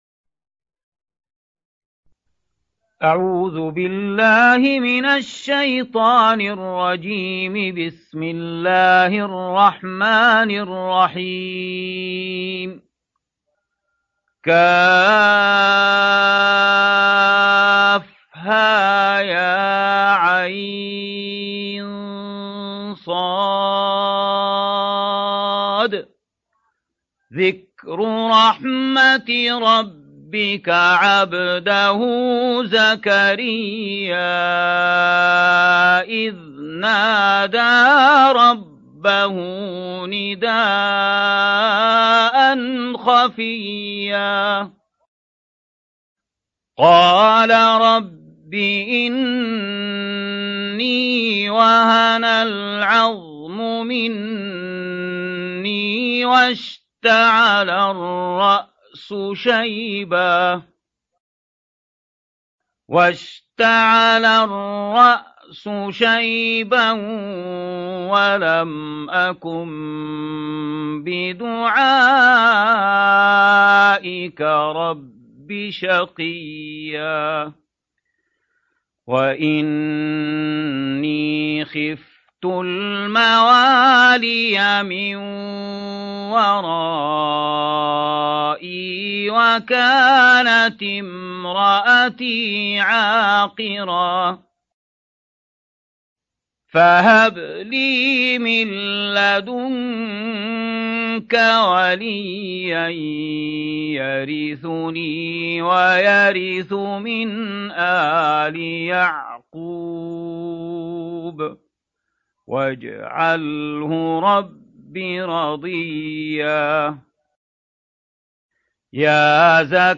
19. سورة مريم / القارئ